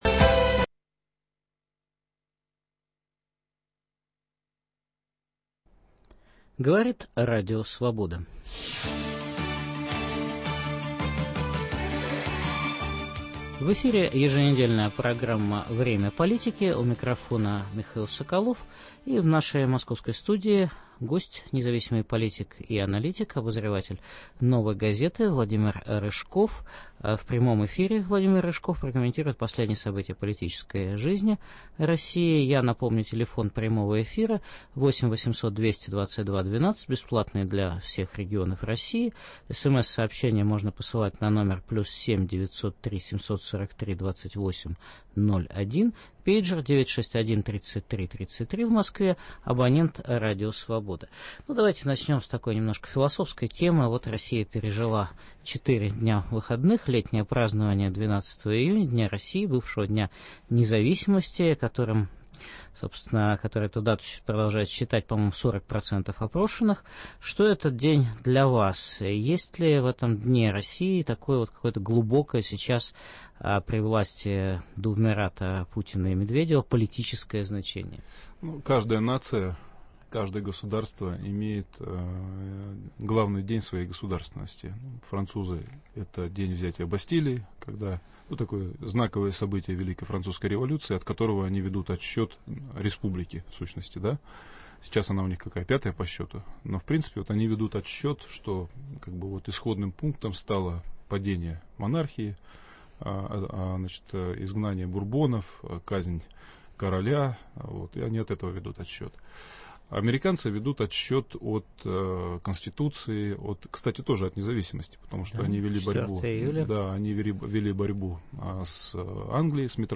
В прямом эфире Владимир Рыжков комментирует последние события политической жизни России и новые попытки объединения демократических сил.